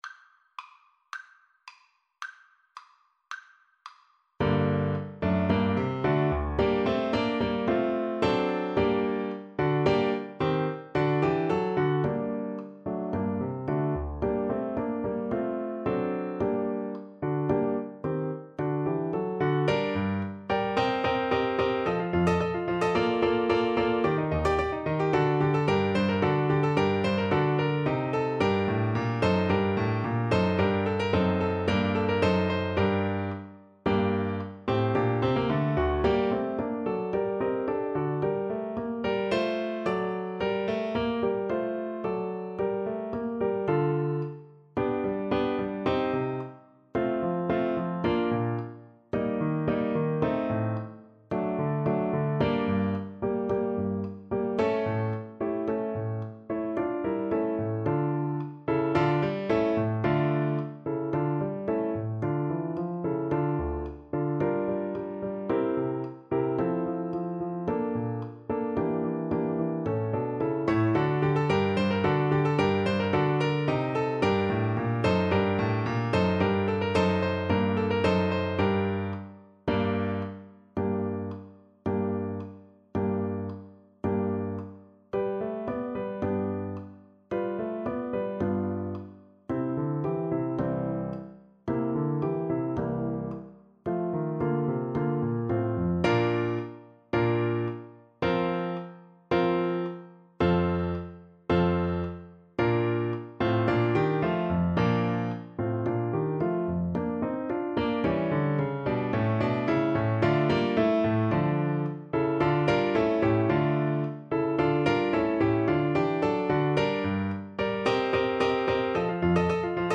= 110 Presto (View more music marked Presto)
Classical (View more Classical Tenor Saxophone Music)